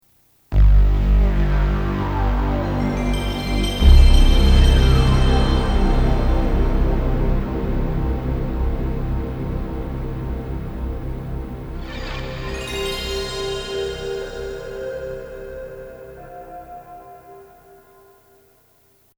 • Качество: 192, Stereo
саундтреки
без слов
колокольчики
электронные